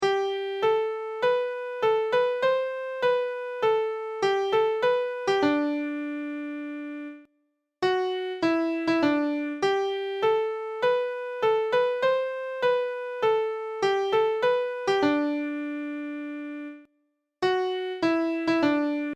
Zulu chant - 4 Parts
Parts 2, 3 then 4 join in, and die away in reverse order to part 1.